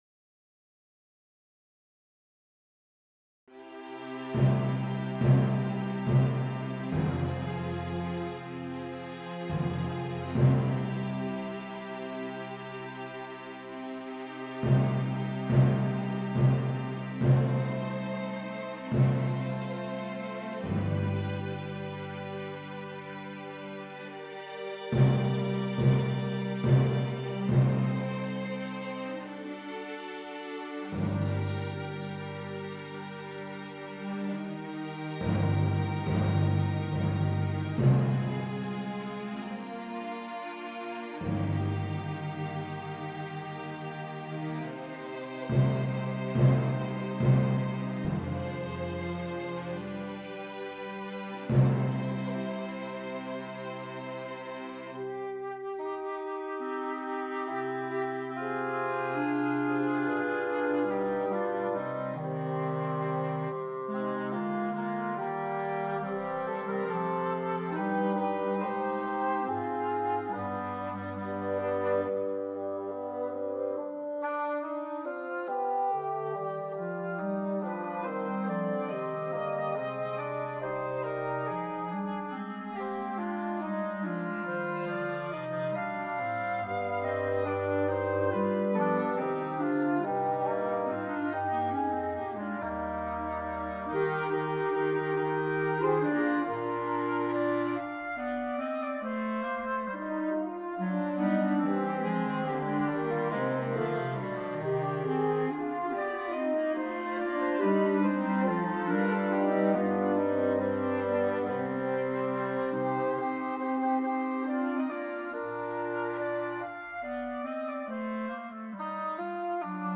Here I've arranged the piece for full symphony orchestra.